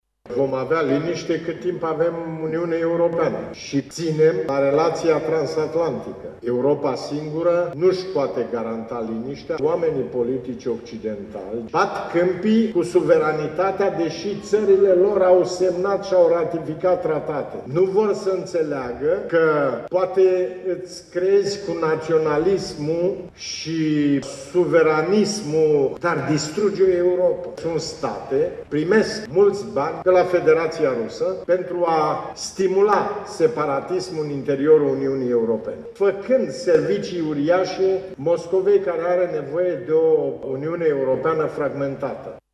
Traian Băsescu a vorbit studenților deaspre „România și perspectivele Uniunii Europene”, într-o conferință ce face parte din seria întâlnirilor „Președinți la UMFST”.